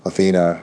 synthetic-wakewords / athena /ovos-tts-plugin-deepponies_Barack Obama_en.wav
ovos-tts-plugin-deepponies_Barack Obama_en.wav